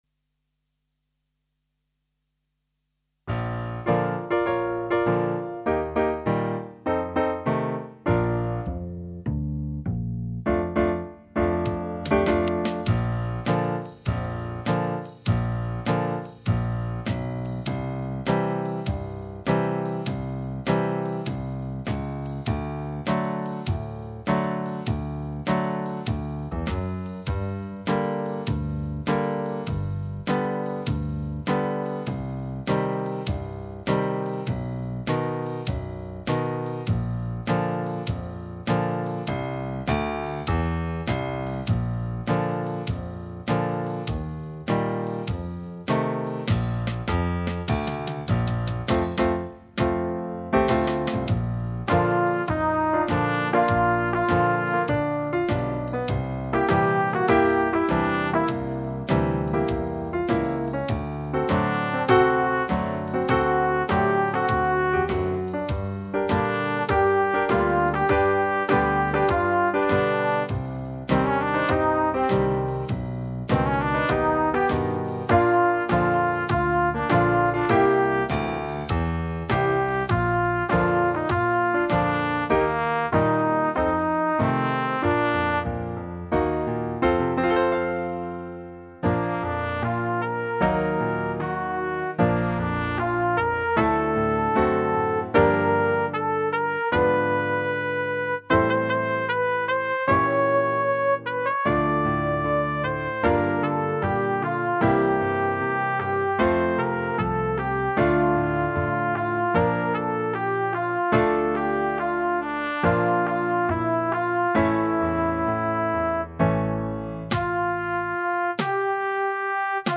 minus Instrument 1